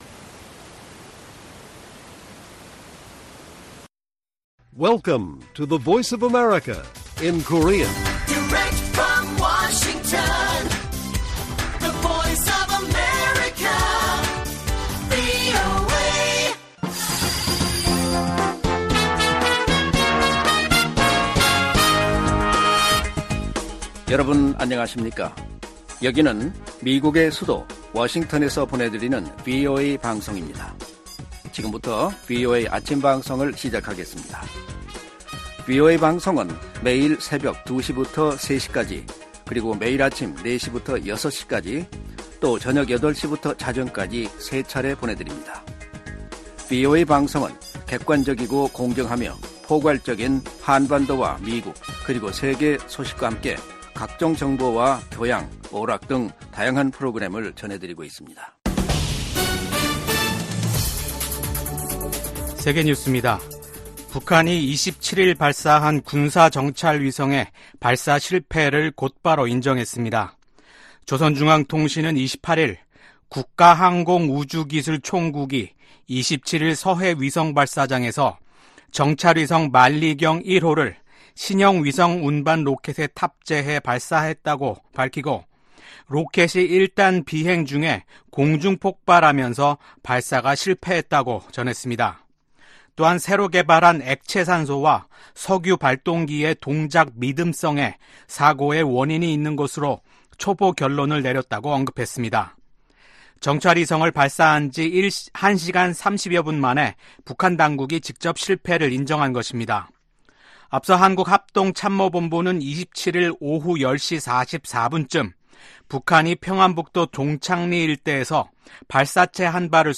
세계 뉴스와 함께 미국의 모든 것을 소개하는 '생방송 여기는 워싱턴입니다', 2024년 5월 28일 아침 방송입니다. '지구촌 오늘'에서는 한국과 일본, 중국, 3국 정상회의가 약 4년 반 만에 한국 서울에서 개최됐습니다. 3국 정상들이 상호 협력을 강조한 소식 전해드리고, '아메리카 나우'에서는 메모리얼 데이 국경일을 맞아 미국에선 다양한 행사 이야기 살펴보겠습니다.